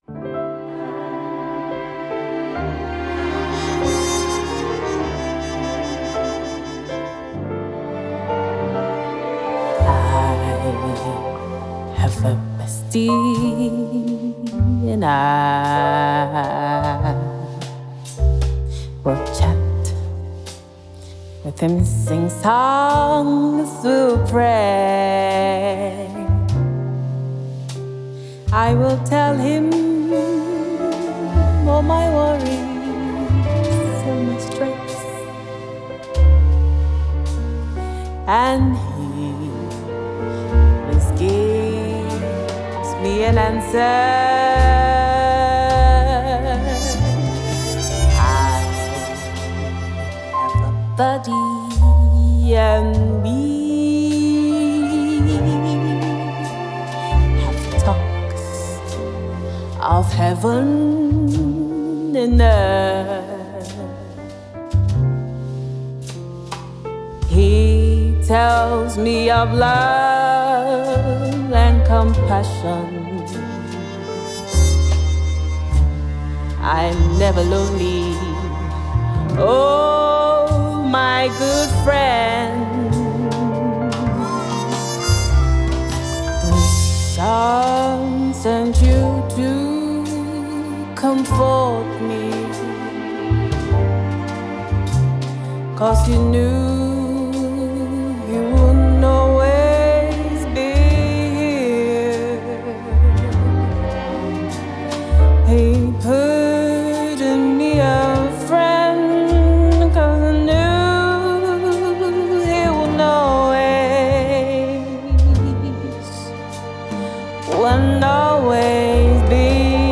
Nigerian fast-rising gospel music minister